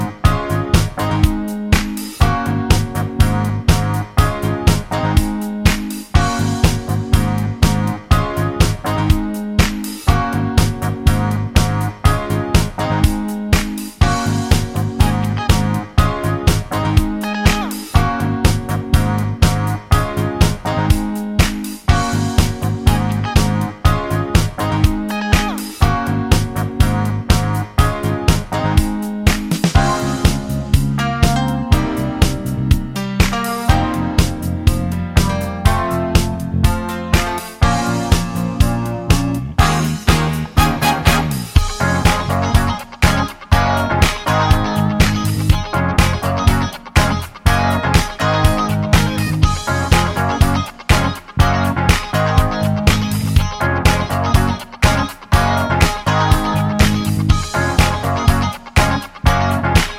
no Backing Vocals Disco 3:40 Buy £1.50